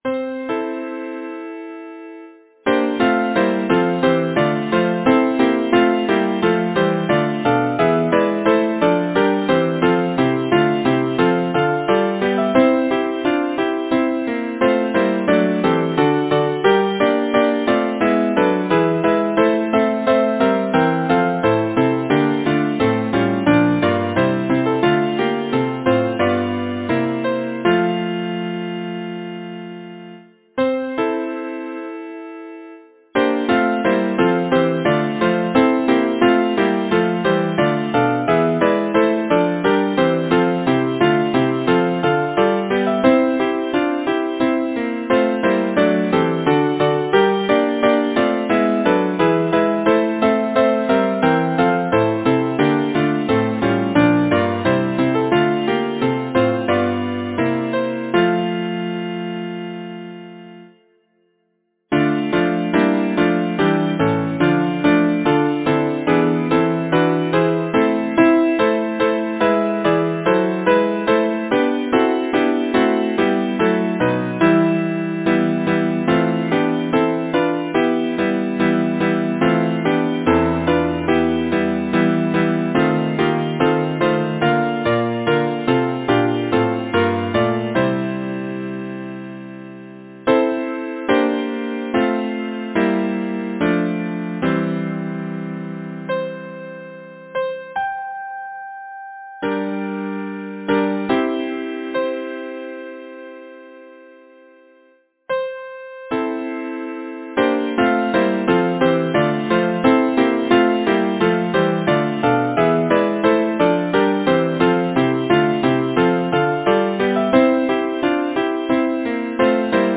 Title: Call of the Breeze Composer: Cliffe Forrester Lyricist: Elizabeth Forrester Number of voices: 4vv Voicing: SATB Genre: Secular, Partsong
Language: English Instruments: A cappella